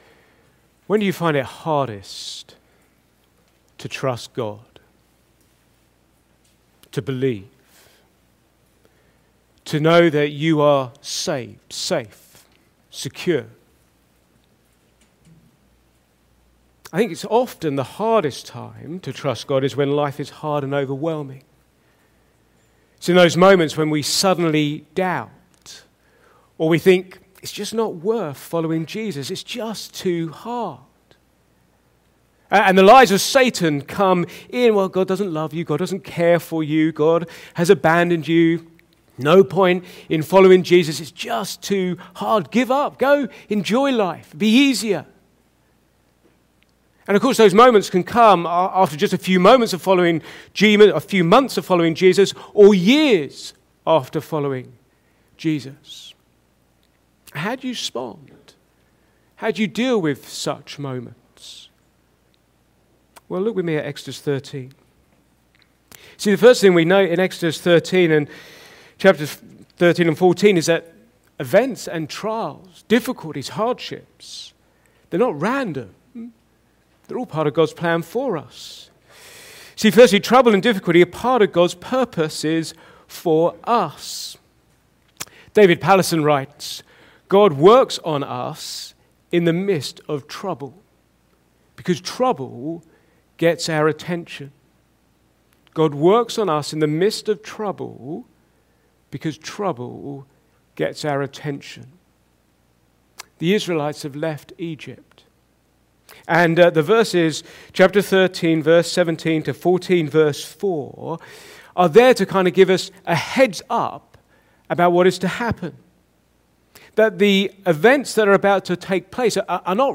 Back to Sermons The parting